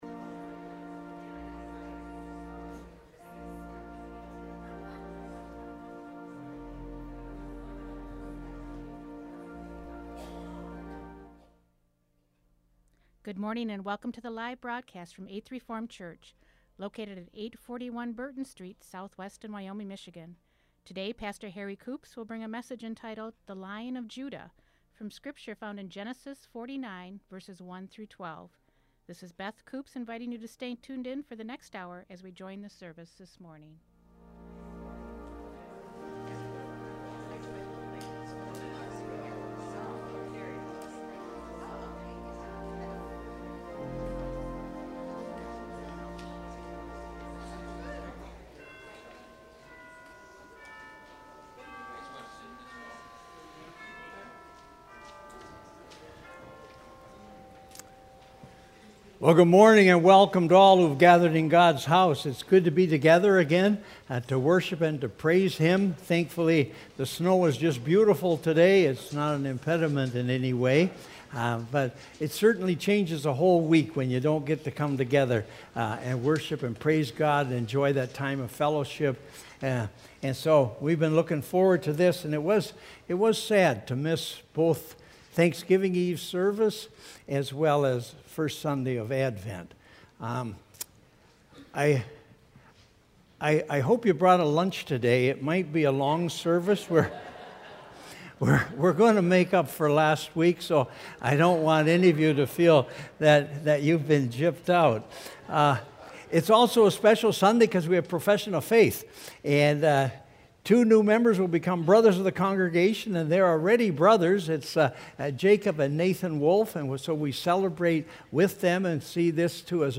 Worship Services | Eighth Reformed Church